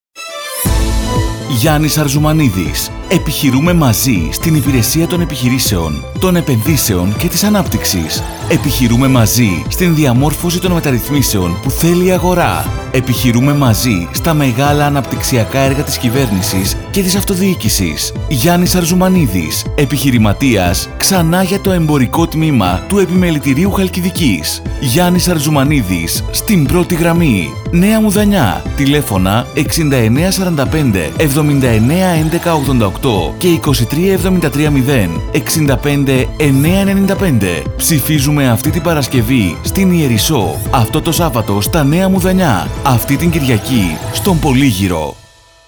ΗΧΗΤΙΚΑ  ΕΝΗΜΕΡΩΤΙΚΑ ΜΗΝΥΜΑΤΑ